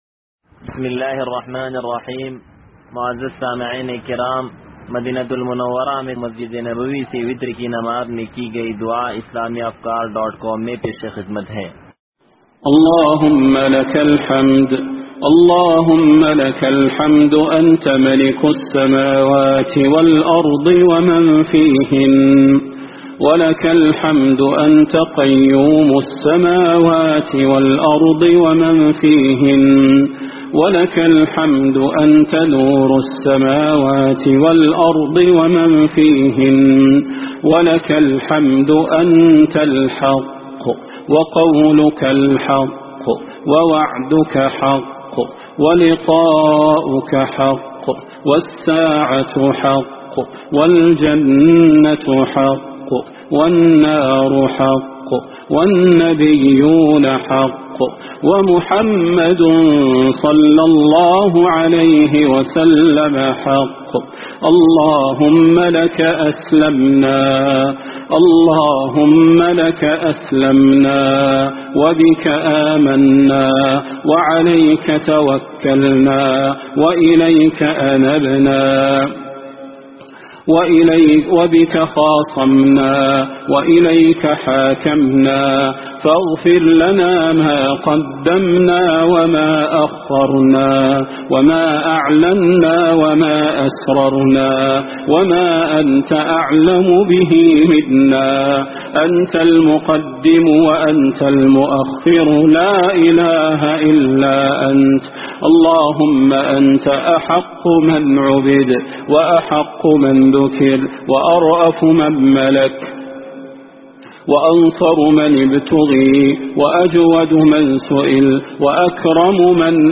مدينة المنوّرة دعاء – 23 رمضان 1442